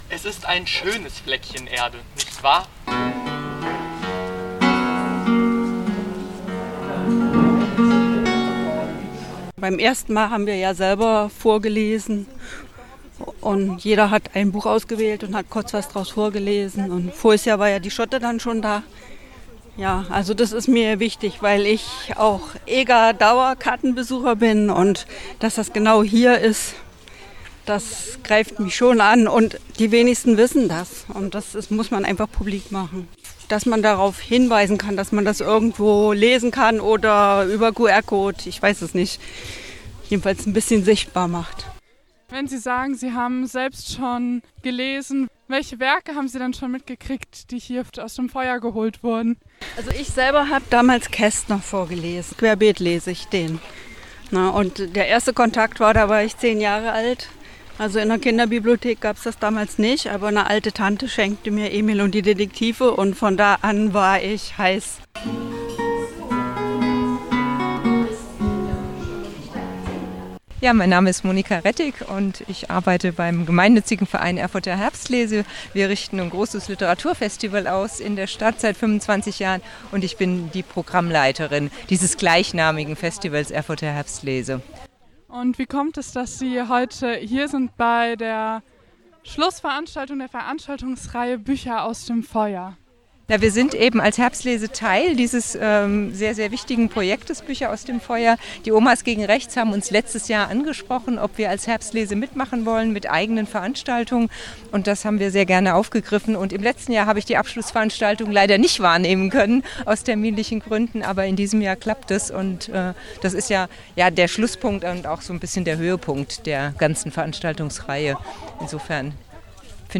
Die Veranstaltungsreihe �Bücher aus dem Feuer� fand am 29. Juni 2022 ihren Abschluss auf der Ega. Schauspieler:innen des Jugendtheaters SCHOTTE haben Texte gelesen und wiederbelebt - Bücher von Autor*innen, deren Werke vor 89 Jahren hier von Nationalsozialisten verbrannt wurden. Radio F.R.E.I. sammelte im egapark Eindrücke, Stimmen und Meinungen rund um die Lesung.